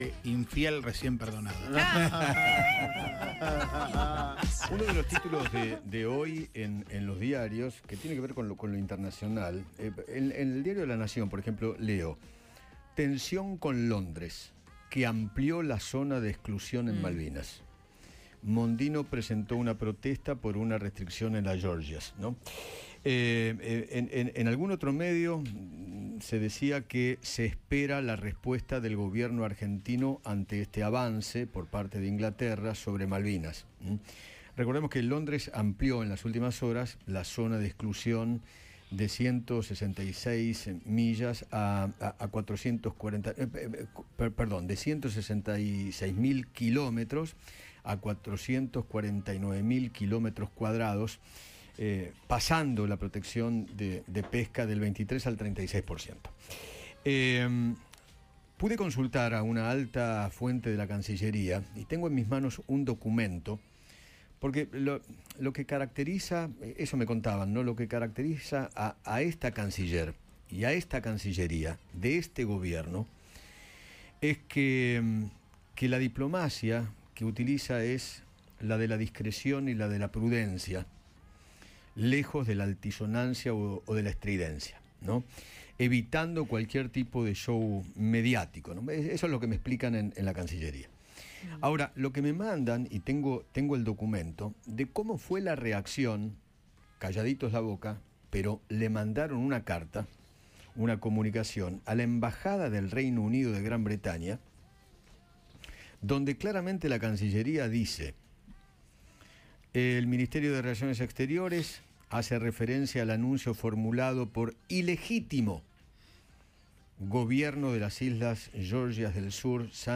“El ilegítimo gobierno”, Eduardo Feinmann leyó el duro documento de Argentina contra el Reino Unido por Malvinas - Eduardo Feinmann